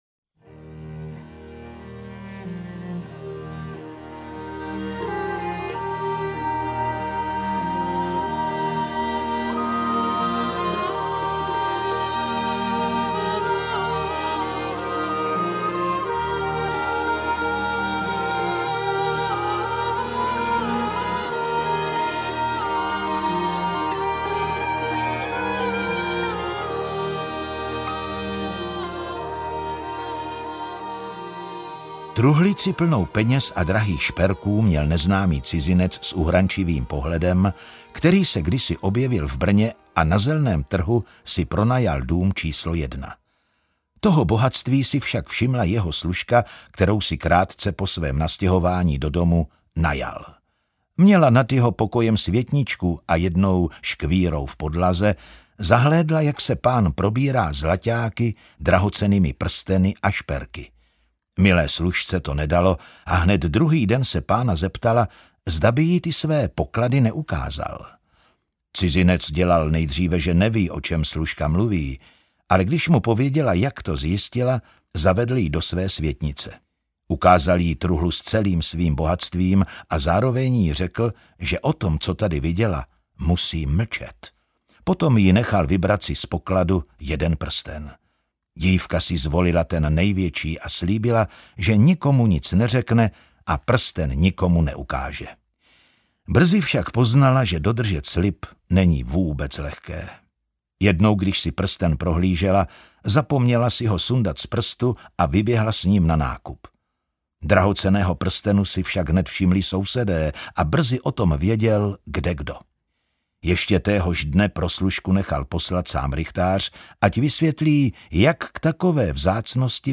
50 moravských pověstí audiokniha
Ukázka z knihy